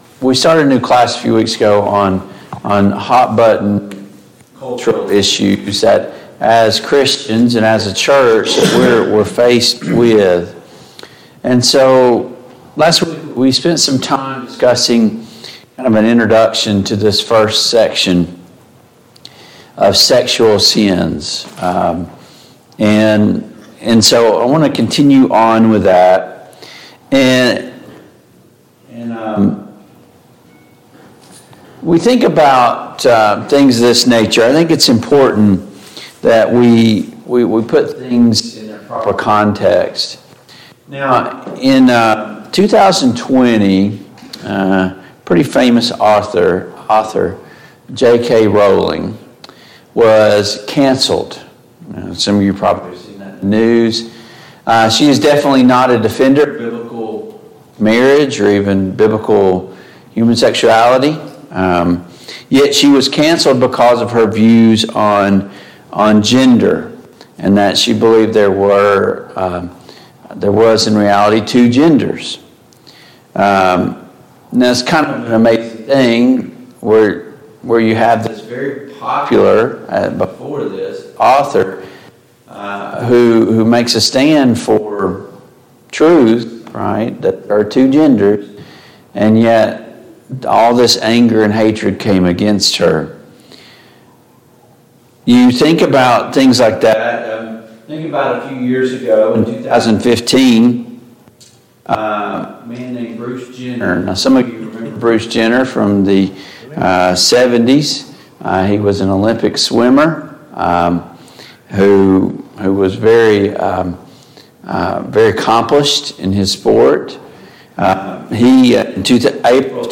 Service Type: Sunday Morning Bible Class Topics: Fornication , Marriage , Sexual sin